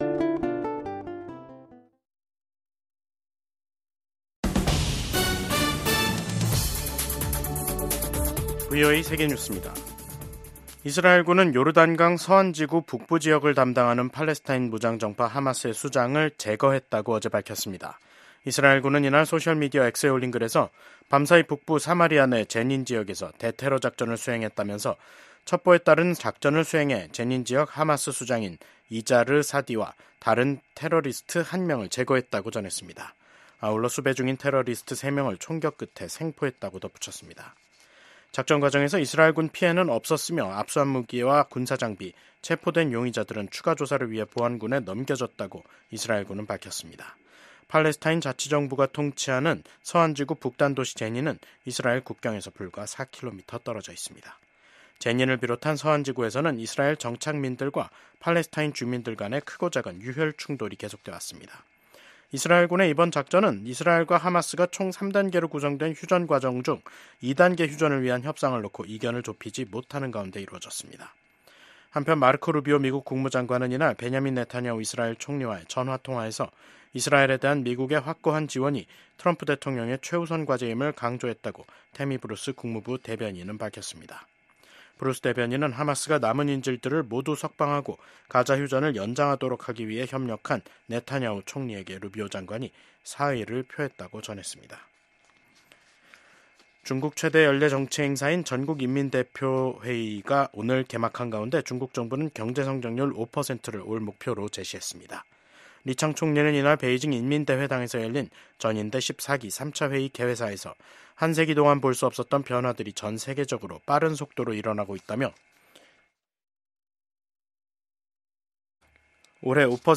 VOA 한국어 간판 뉴스 프로그램 '뉴스 투데이', 2025년 3월 5일 3부 방송입니다. 도널드 트럼프 미국 대통령이 2기 행정부 출범 이후 첫 의회 상하원 합동회의 연설에서 ‘미국이 돌아왔다’고 강조했습니다. 트럼프 대통령은 이번 상하원 합동회의 연설에서 북한은 언급하지 않았고, 동맹국인 한국에 대해서는 부당하게 높은 대미 관세를 부과하고 있다고 밝혔습니다.